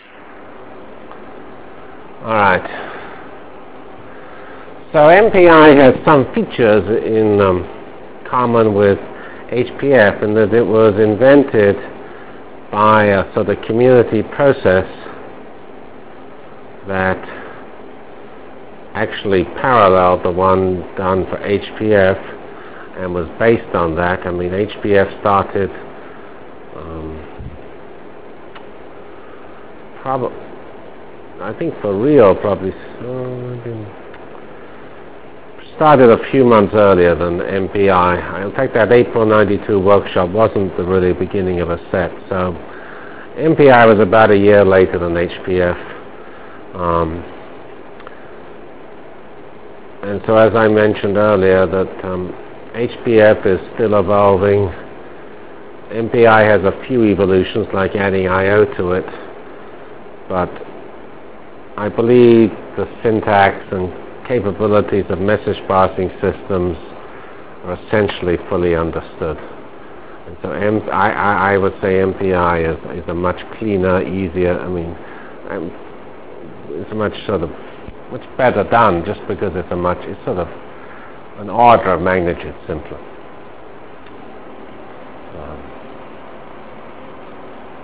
From CPS615-Initial Lecture on MPI ending with discussion of basic MPI_SEND Delivered Lectures of CPS615 Basic Simulation Track for Computational Science